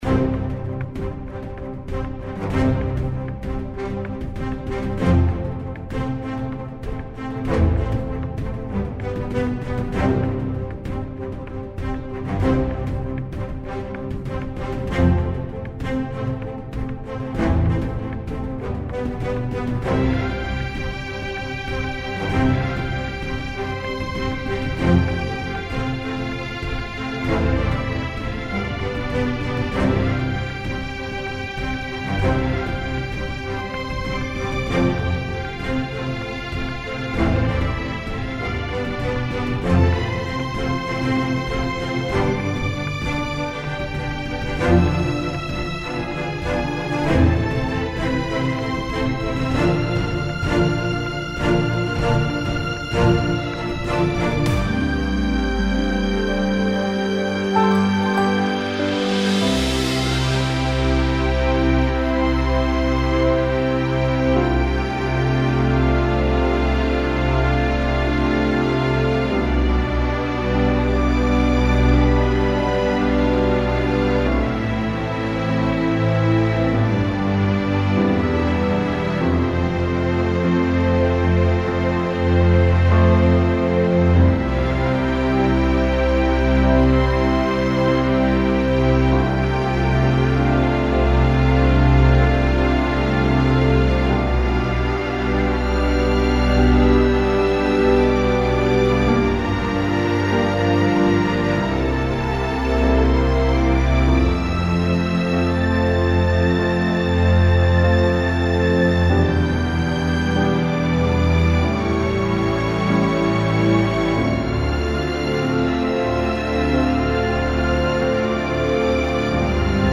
Largo [40-50] peur - orchestre symphonique - - -